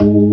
FST HMND G#2.wav